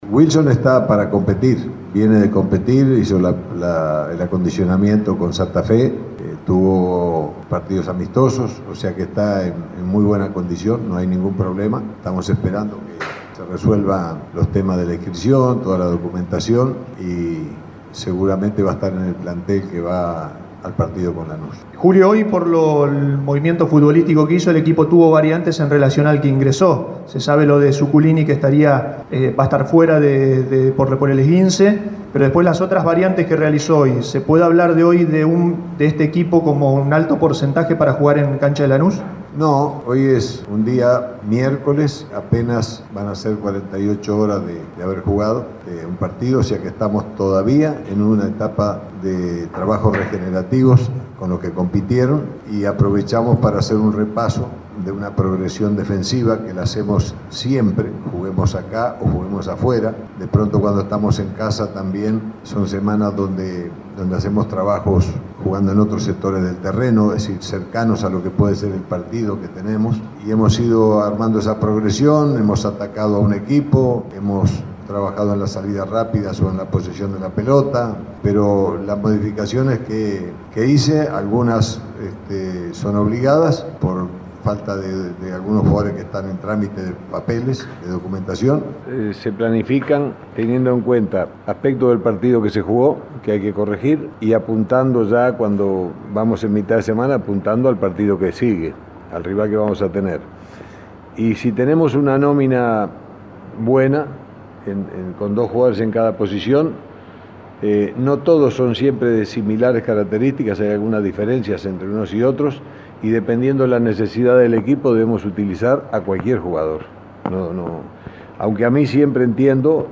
• Julio Comesaña – Conferencia de prensa.